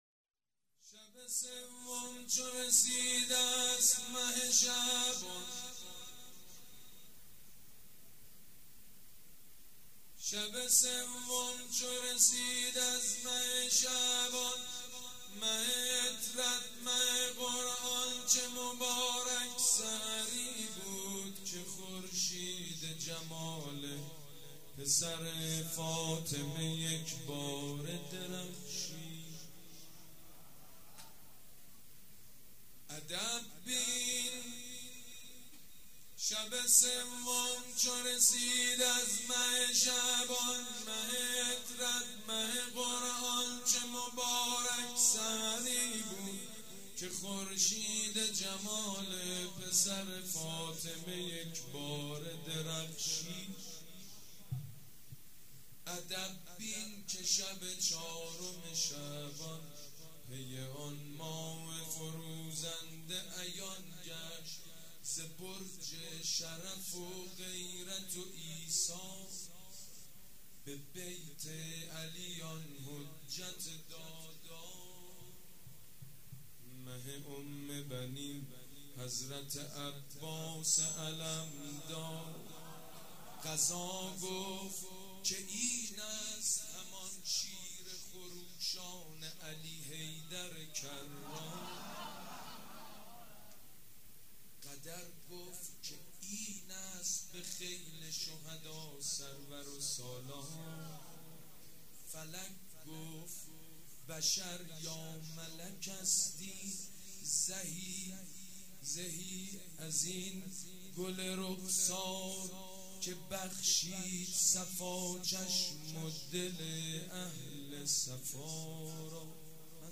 شعر خوانی: شب سوم چو رسید از مه شعبان